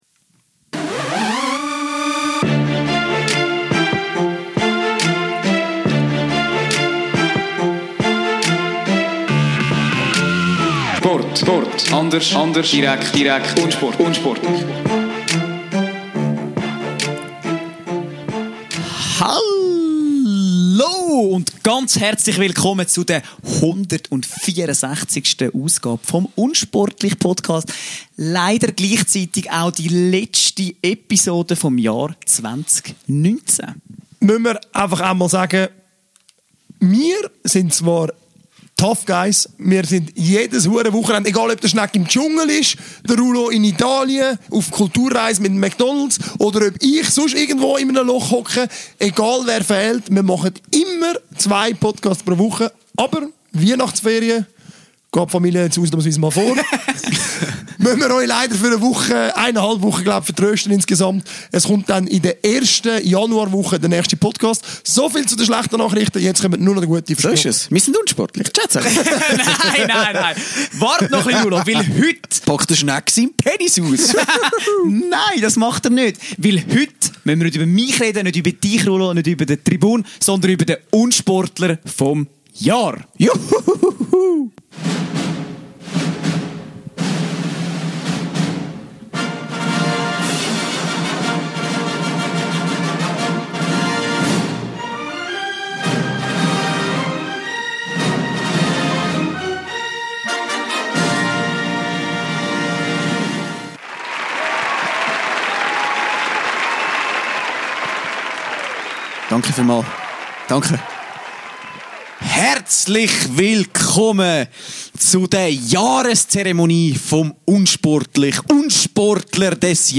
Die Lösung dieses einfachen Rätsels: Das letzte Interview von 2019 gehört Ryan Regez – Unsportler des Jahres.